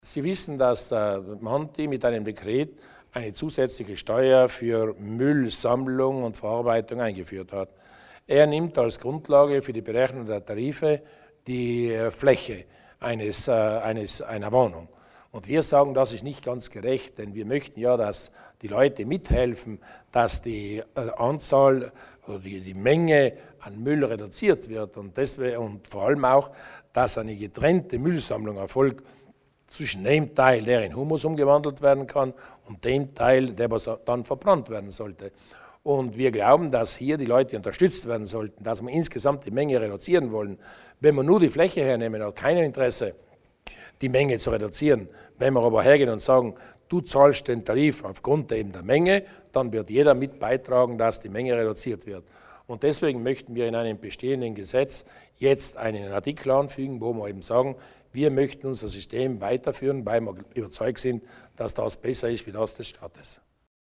Landeshauptmann Durnwalder zur Anwendung der staatlichen Müllsteuer TARES